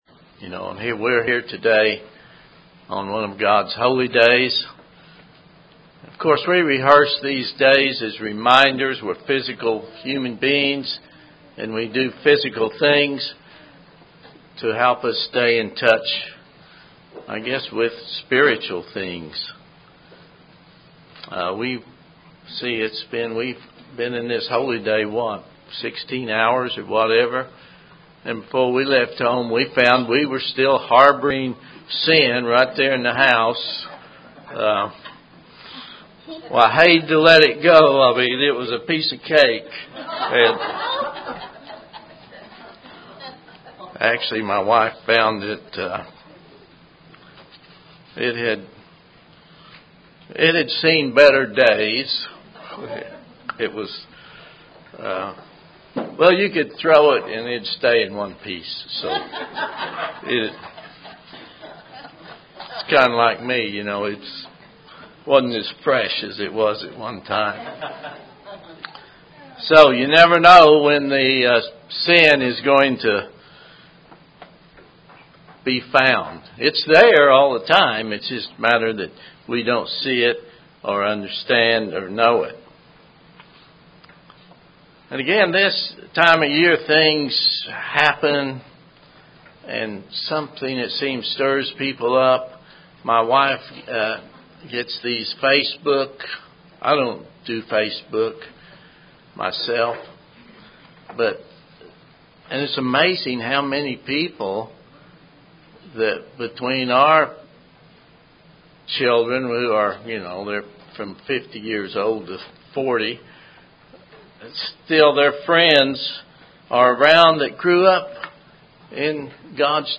Given in Oklahoma City, OK
UCG Sermon Studying the bible?